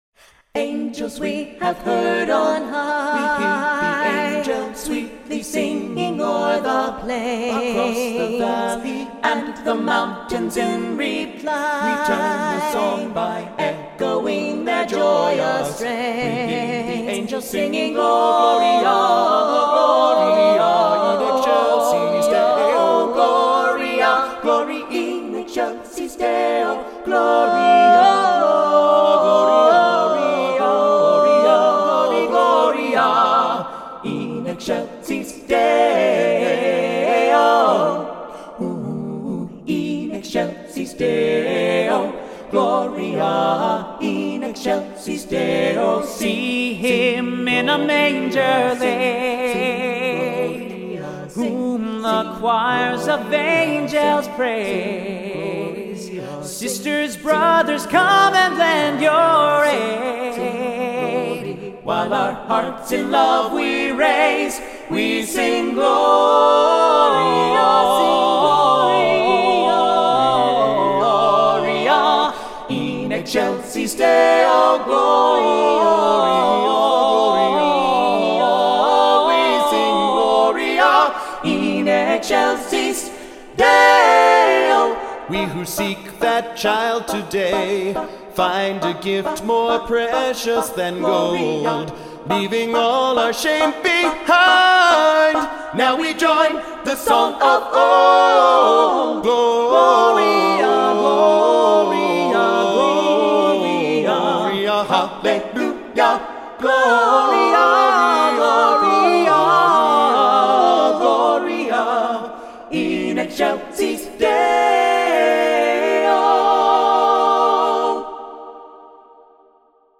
a cappella 3-voice arrangement